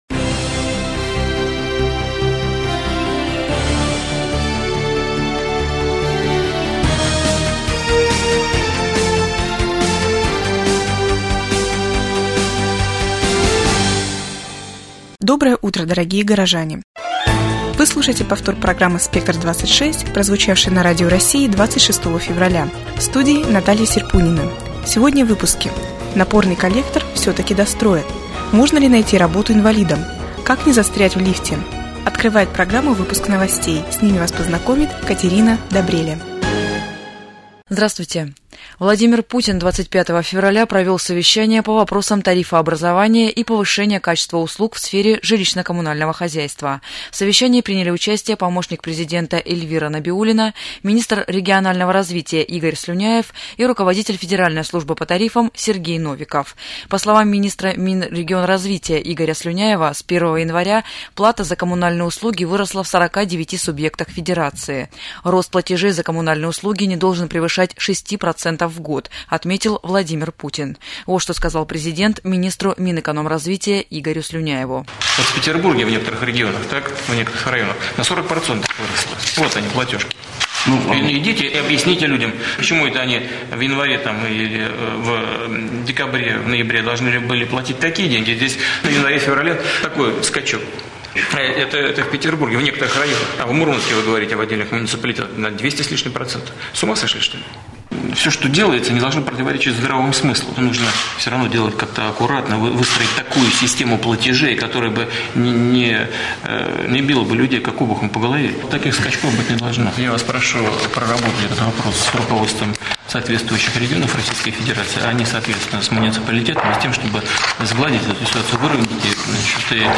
СЛУШАЙ РАДИОПРОГРАММУ ЗА 26 ФЕВРАЛЯ » Свежее телевидение - Железногорск